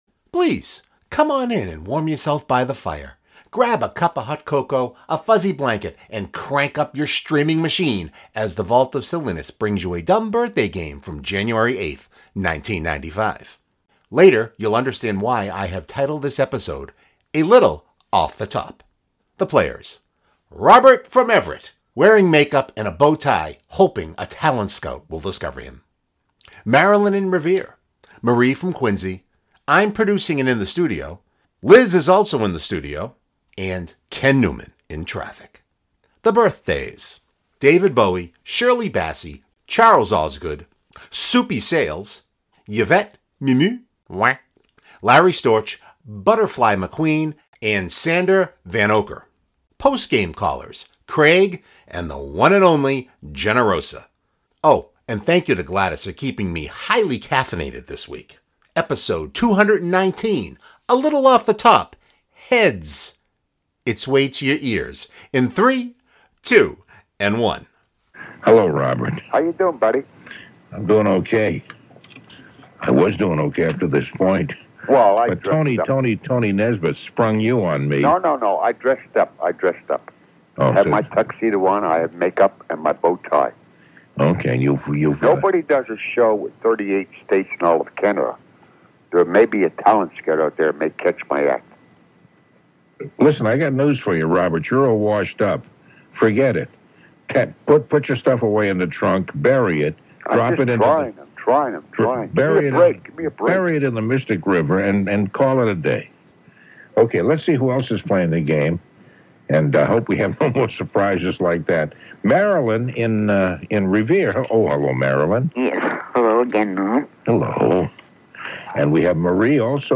Grab a cup of hot cocoa, a fuzzy blanket and crank up your streaming machine as The Vault of Silliness brings you a DBG from January 8th, 1995.